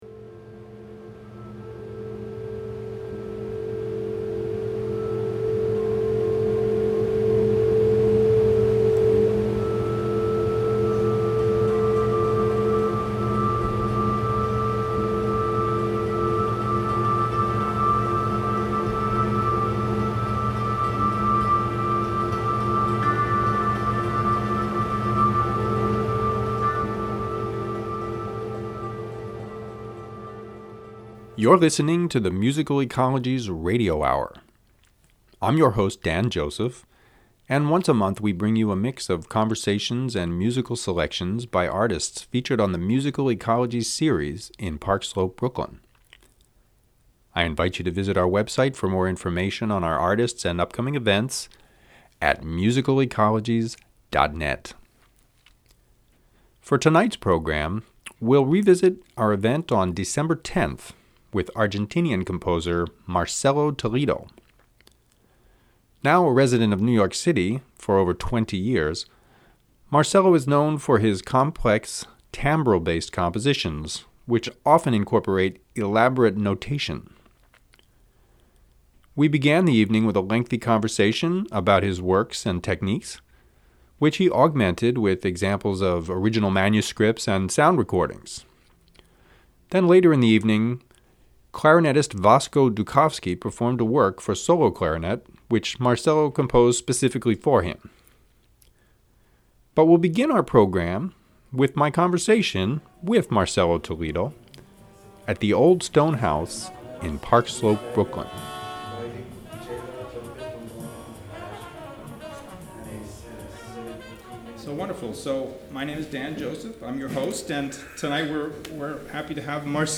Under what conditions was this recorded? The Musical Ecologies Radio Hour is a monthly program that features conversations, live concert recordings, and other audio excerpts featuring artists presented on the Musical Ecologies series in Park Slope, Brooklyn.